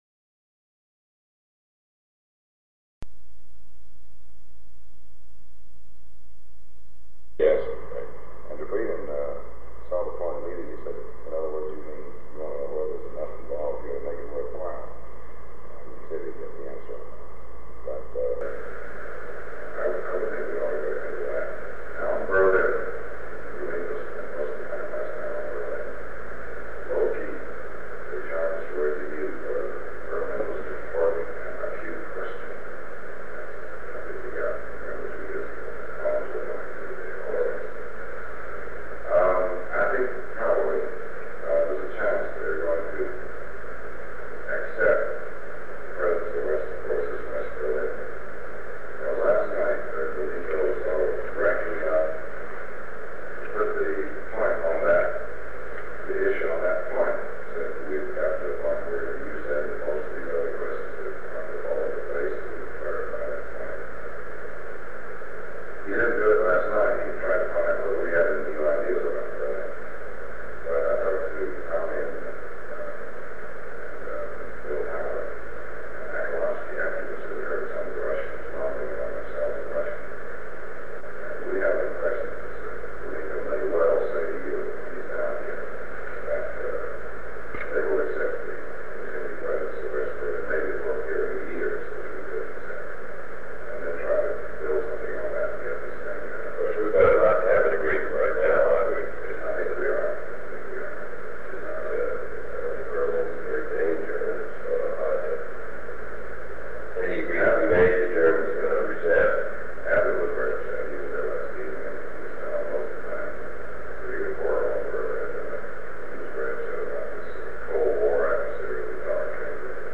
Meeting with Dean Rusk, 4 October 1963 John F. Kennedy Dean Rusk Human Rights and Civil Rights Latin America and the Caribbean Latin America and the Caribbean Foreign Affairs War and Terrorism Sound recording of a meeting held on October 4, 1963, between President John F. Kennedy and Secretary of State Dean Rusk. Main topics of discussion are Secretary Dean Rusk’s meeting with Soviet Ambassador to the United States Anatoly Dobrynin regarding Berlin, Germany; Secretary Rusk’s talks with Soviet Minister of Foreign Affairs Andrei Gromyko regarding Laos; President Kennedy’s meeting with Minister Gromyko; a "New York Times" article regarding the race to the moon; and the possibility of sharing space information with the Soviet Union. They also discuss the Caribbean and Latin American regions, the Dominican Republic, Ecuador, and civil rights.
Secret White House Tapes | John F. Kennedy Presidency Meetings: Tape 114/A49.